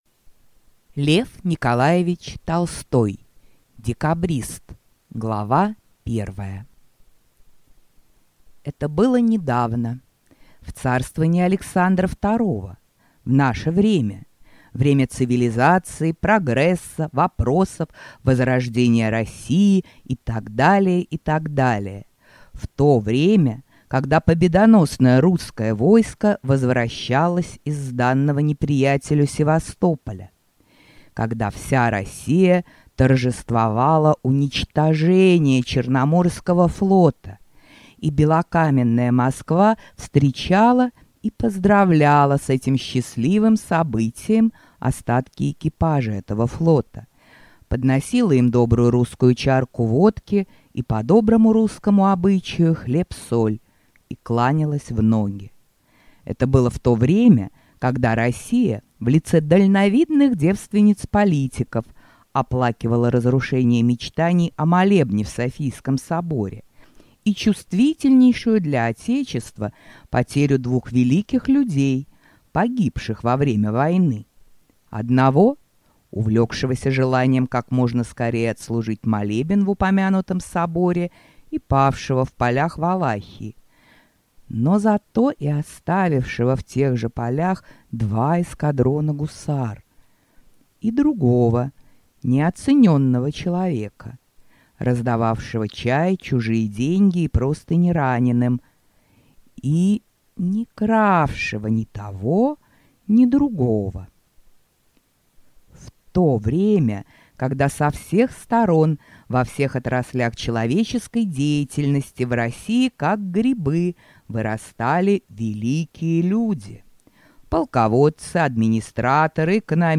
Аудиокнига Декабристы | Библиотека аудиокниг